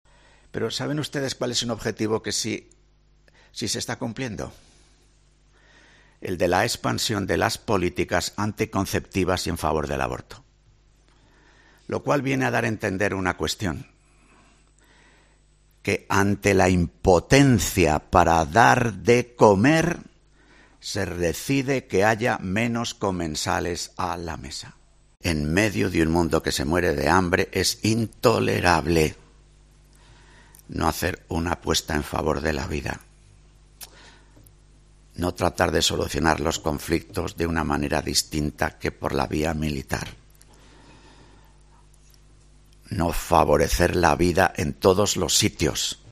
El prelado de Valladolid presenta la campaña de Manos Unidas que en 2023 pretende desarrollar 11 proyectos por la igualdad